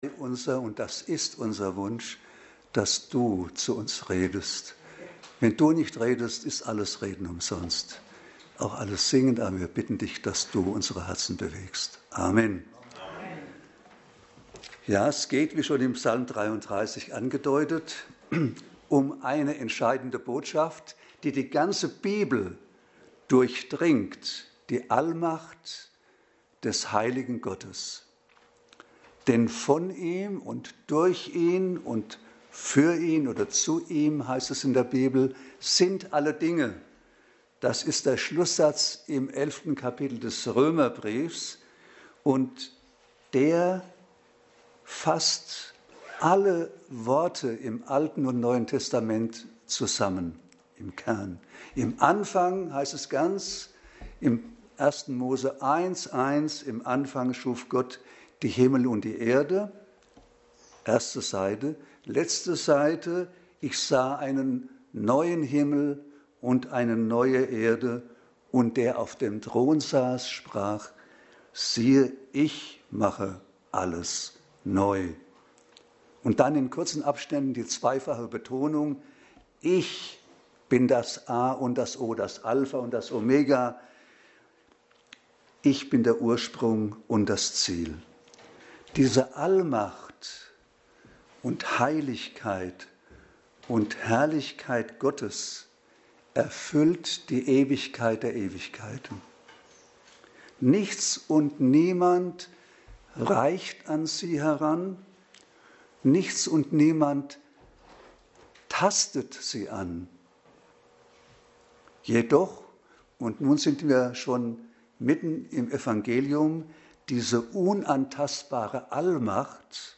Korinther 11,1-16 Dienstart: Predigt 1.